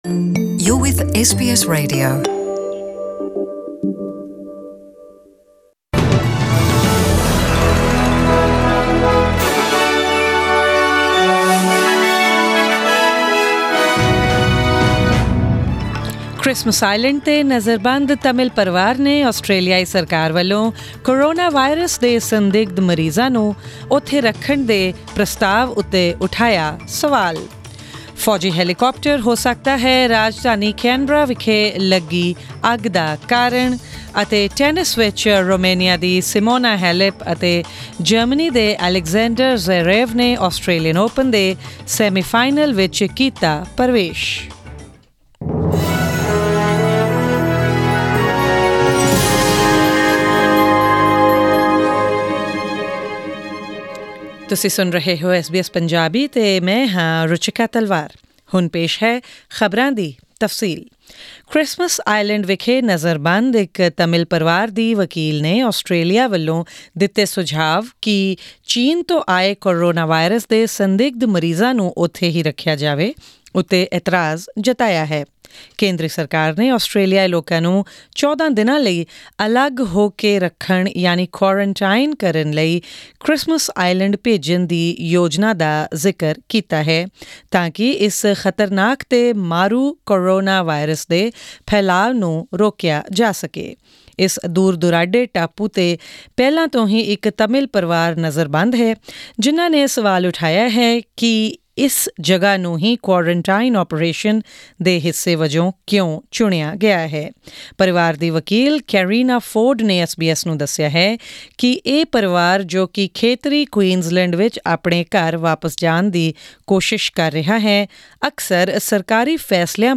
Presenting the major news stories of today with updates on sports, currency exchange rates and the weather forecast for tomorrow.